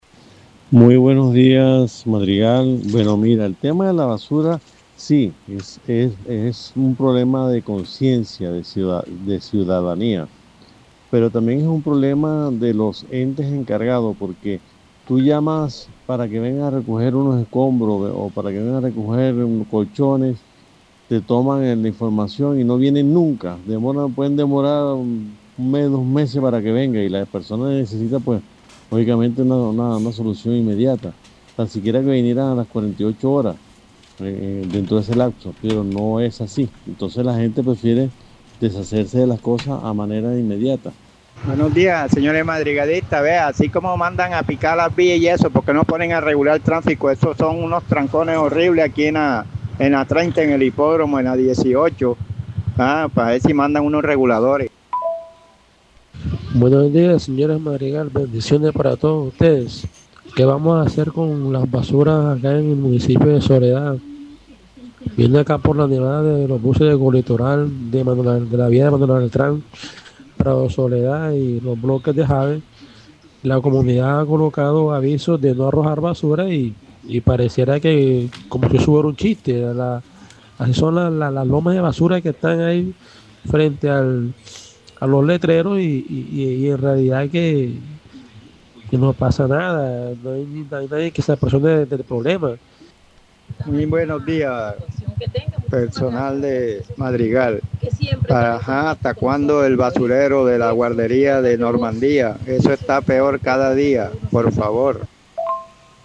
ESCUHE LAS QUEJAS DE OYENTES- BASURAS Y CAOS DE TRASNITO EN LAS VÍAS